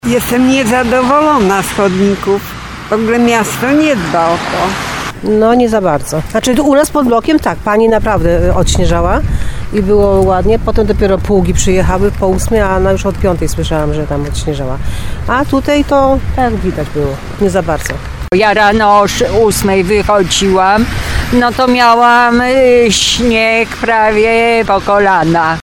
24sonda-chodniki.mp3